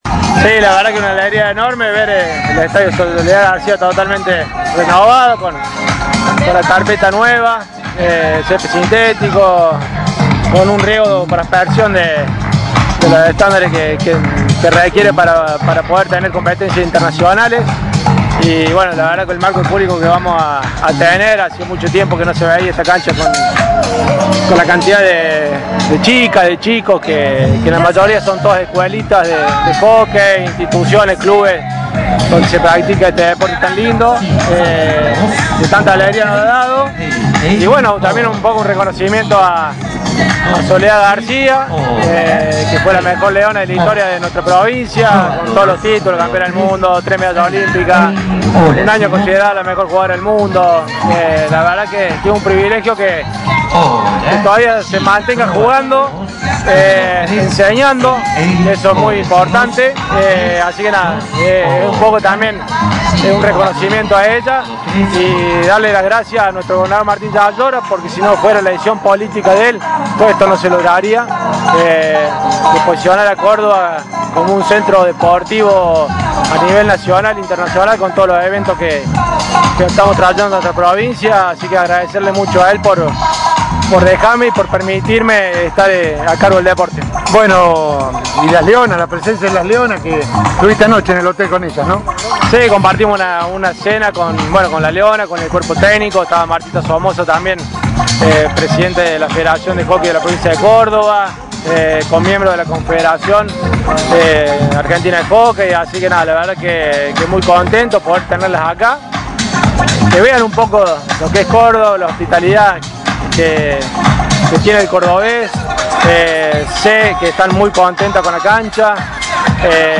Audio de la nota con AGUSTÍN CALLERI: